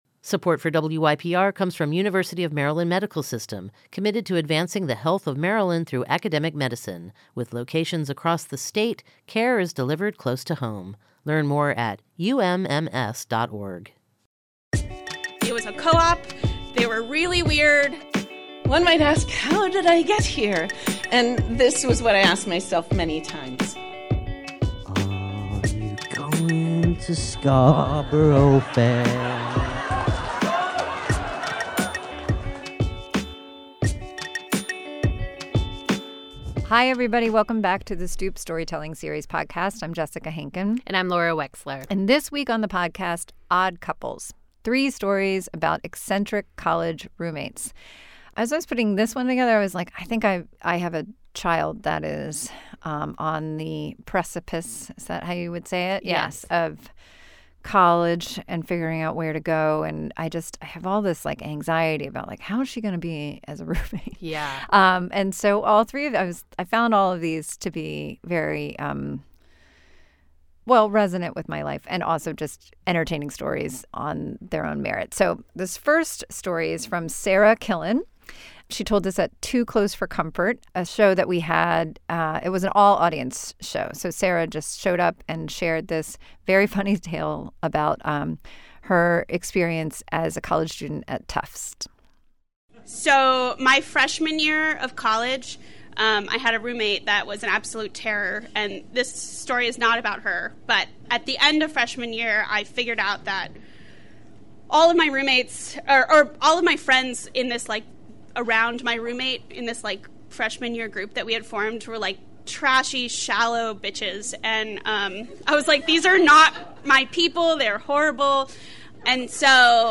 Podcasts The Stoop Storytelling Series podcast captures the spirit of The Stoop live shows with bi-weekly episodes featuring Stoop stories and narration from fo